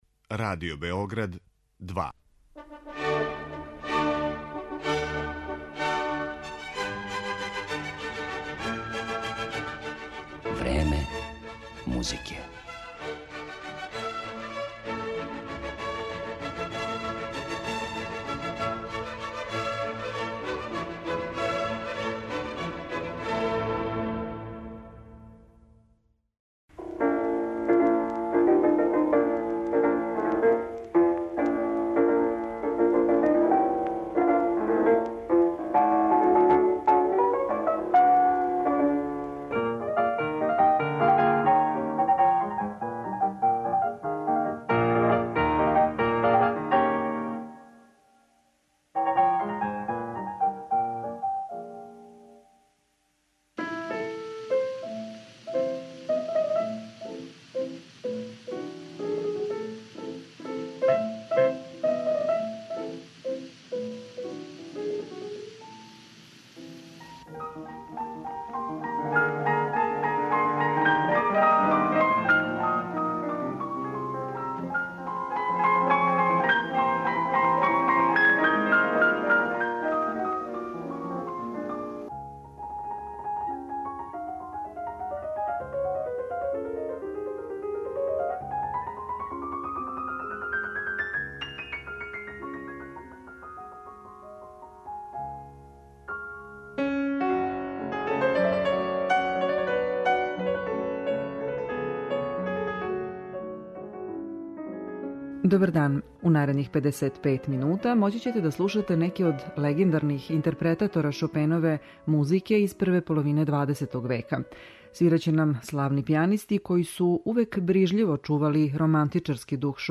Славни пијанисти изводе Шопенову музику
Свираће славни пијанисти: Игнац Падеревски, Сергеј Рахмањинов, Алфред Корто, Клаудио Арау, Дину Липати, Владимир Хоровиц и Артур Рубинштајн.